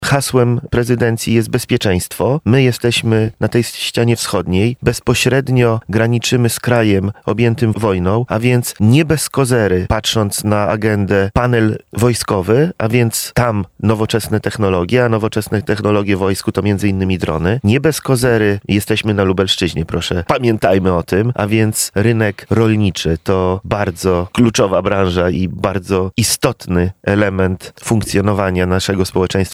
Te panele można skojarzyć z tym co dzisiaj się dzieje w naszym kraju z perspektywy naszej prezydencji- mówił w Porannej Rozmowie Radia Centrum dr Mariusz Filipek, zastępca Rzecznika Małych i Średnich Przedsiębiorców, Pełnomocnik Ministra Rozwoju i Technologii ds. deregulacji i dialogu gospodarczego.